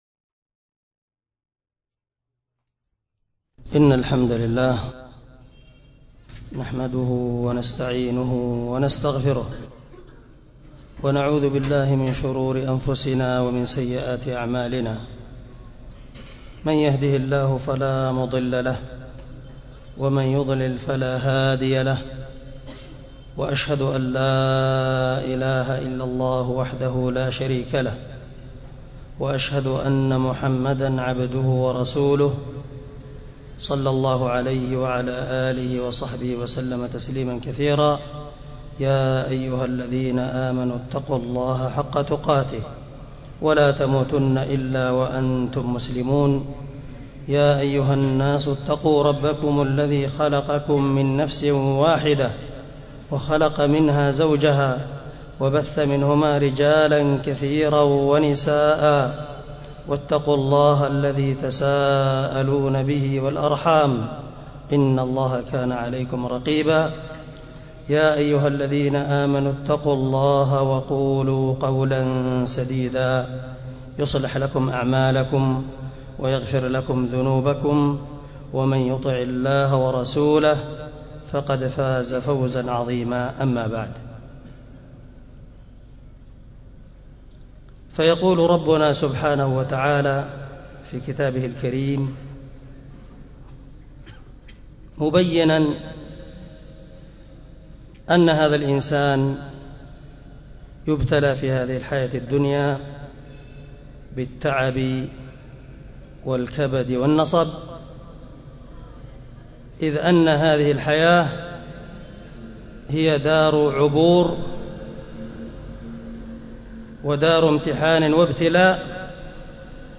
محاضرة بعنوان وبدا لهم من الله ما لم يكونوا يحتسبون